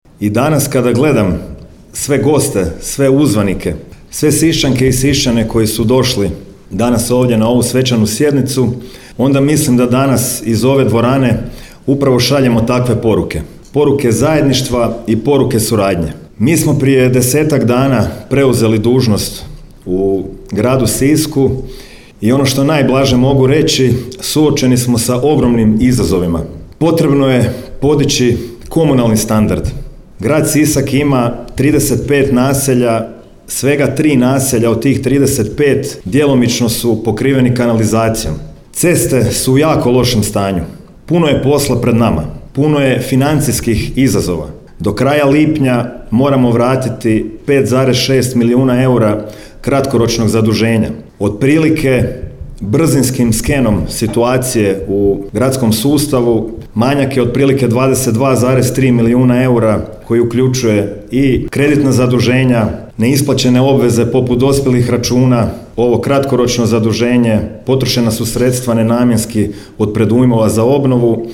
Tim povodom u srijedu, 4. lipnja 2025. godine, održana je svečana sjednica Gradskog vijeća Grada Siska.
Gradonačelnik Domagoj Orlić u uvodnom dijelu svog govora istaknuo je kako se posljednjih mjeseci u Sisku puno govorilo o zajedništvu i o suradnji